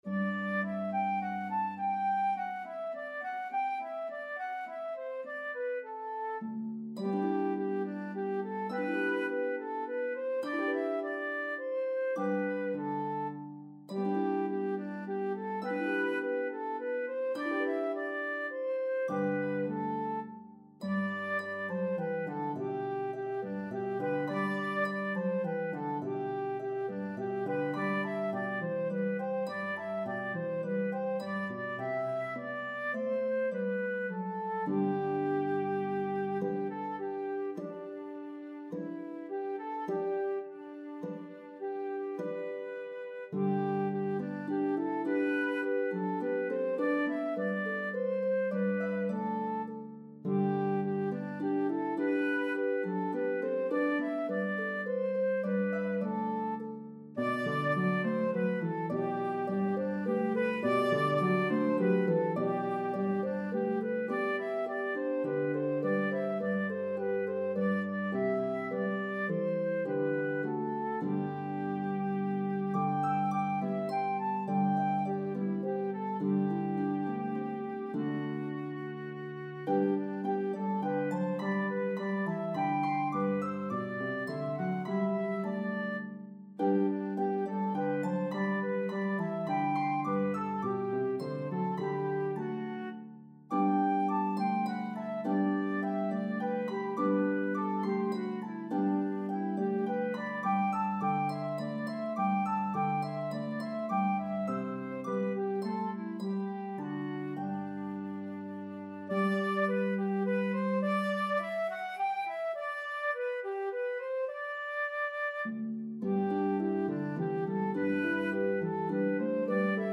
A jubilant arrangement